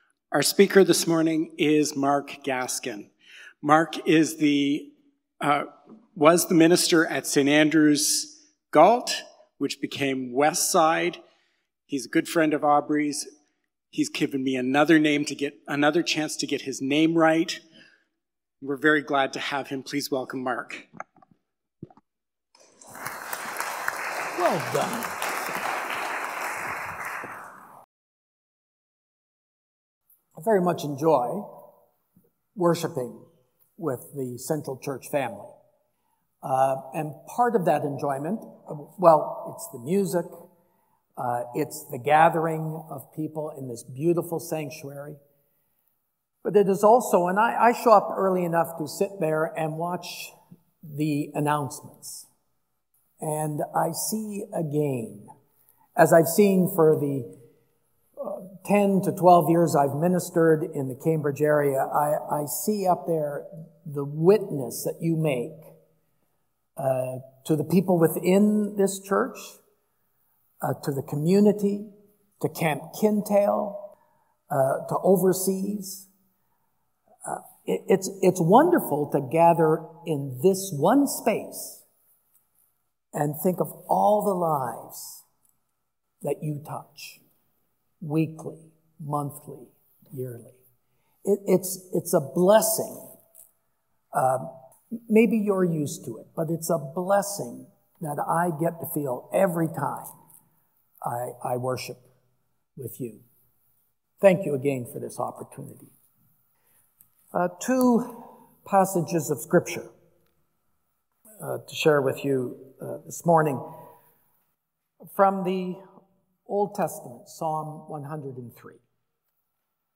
November-3-Sermon.mp3